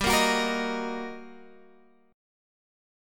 GmM7#5 Chord